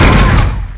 game-source/ParoxysmII/sound/weapons/tsfire.wav at 43c9295fc5ee80339a1cff46be3b5a4caa13ec6c